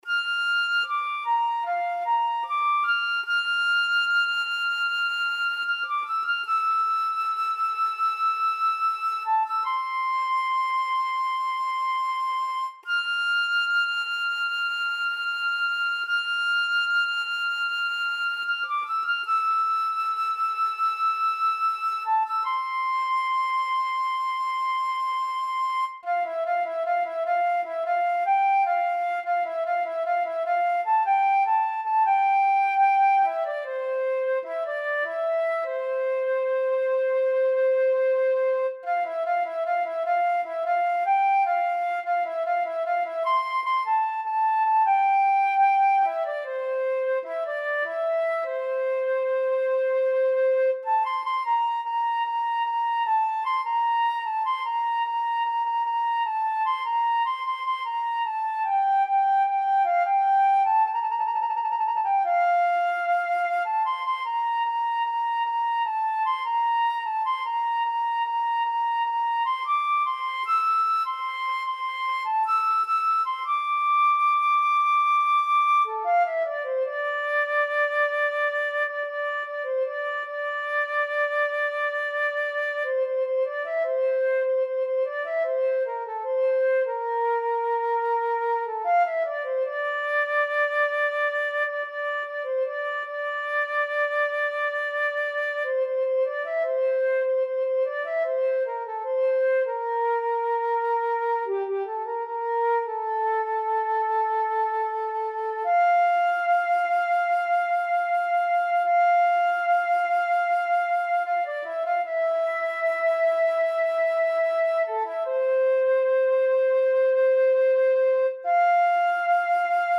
این نت جهت اجرا با فلوت نت نویسی و تنظیم شده است